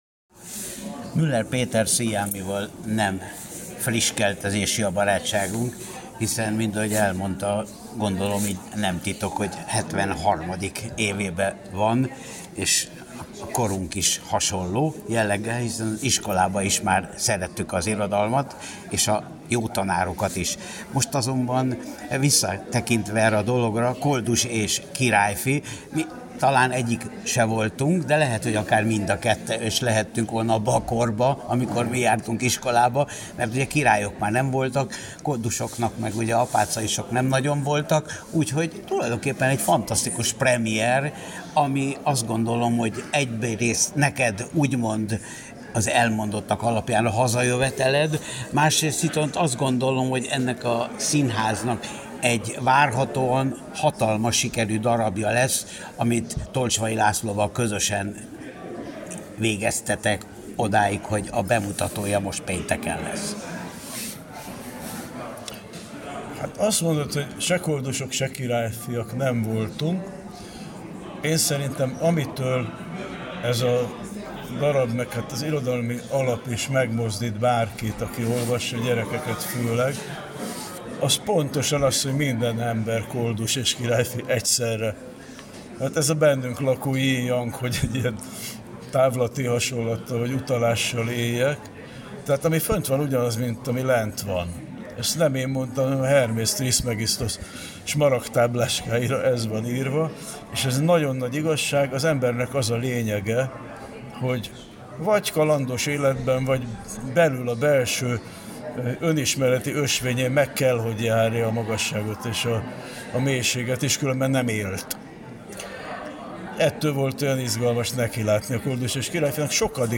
Müller Péter Sziámi és Tolcsvay László musicalje a Magyar Színházban Interjú Müller Péter Sziámival